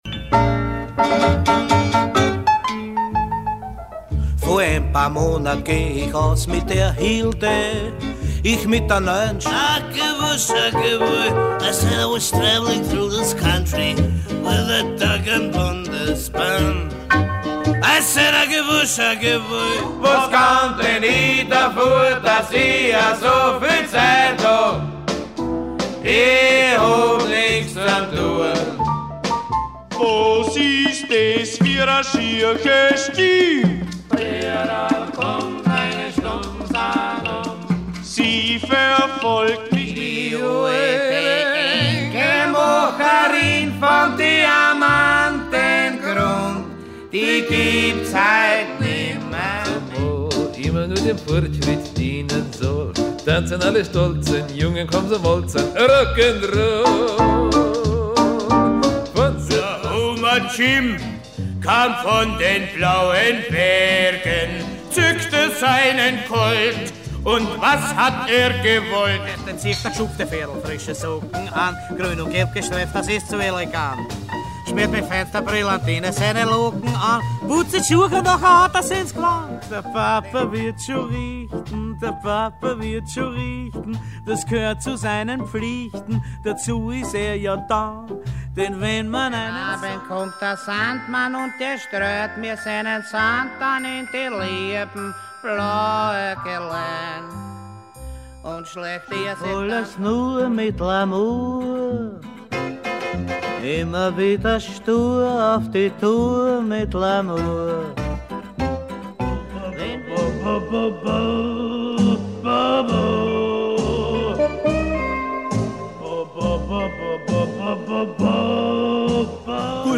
mit kleiner Besetzung und Orchester
Hier hören Sie 10 Sekunden von jedem Lied